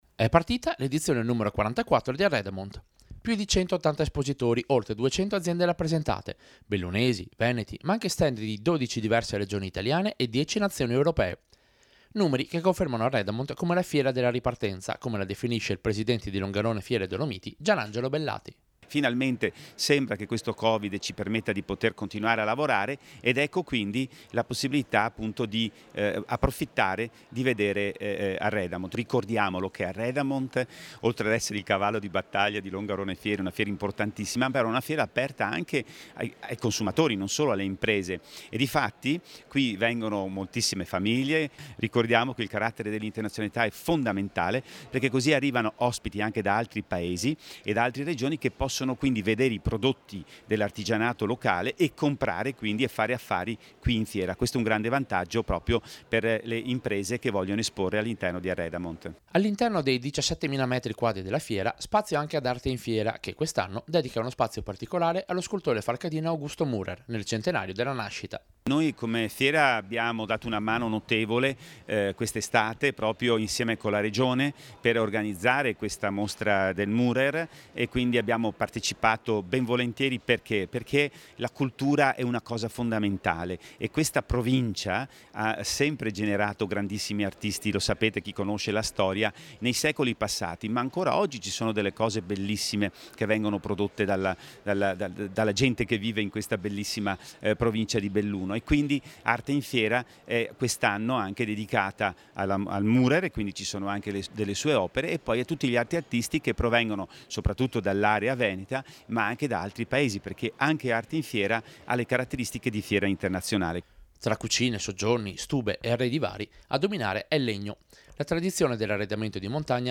ARREDAMONT 2022, L’INAUGURAZIONE E LE INTERVISTE DI RADIO PIU
Radio-Piu-Inaugurazione-Arredamont-2022.mp3